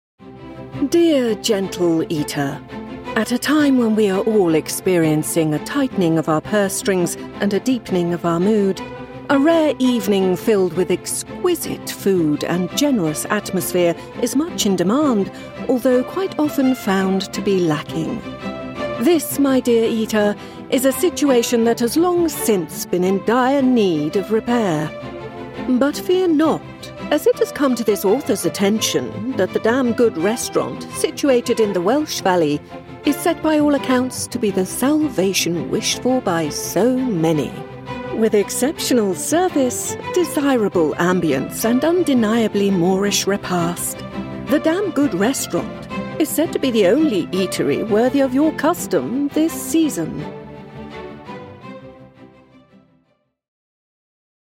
Inglés (Reino Unido)
Juguetón
Elegante
Amistoso